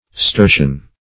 Sturtion \Stur"tion\, n.